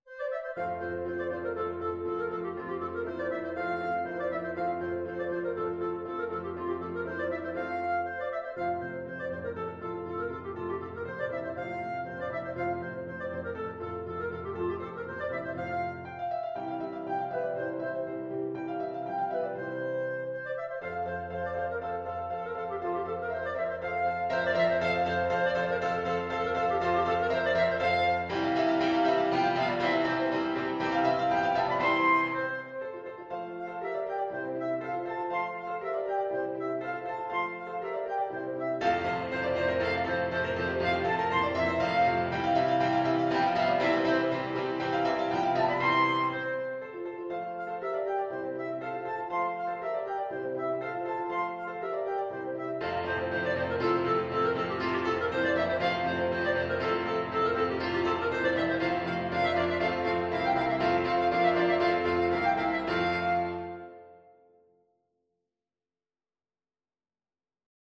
Clarinet
F major (Sounding Pitch) G major (Clarinet in Bb) (View more F major Music for Clarinet )
2/4 (View more 2/4 Music)
Classical (View more Classical Clarinet Music)